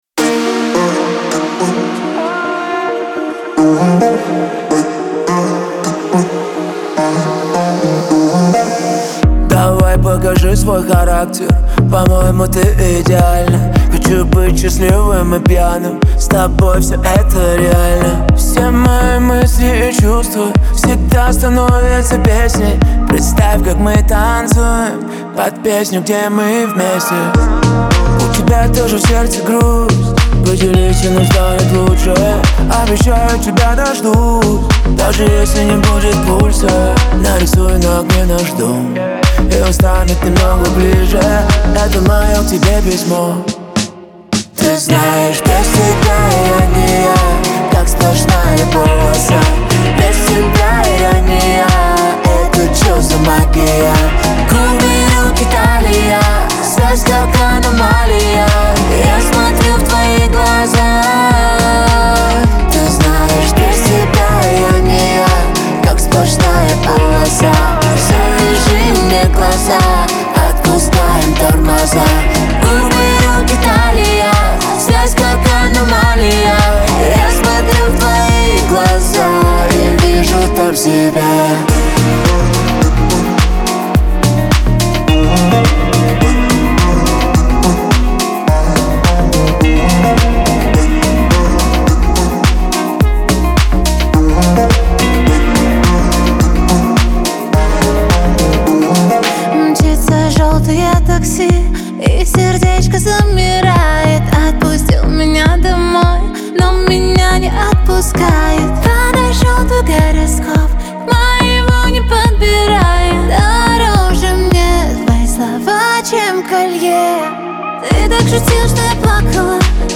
диско , pop
дуэт , эстрада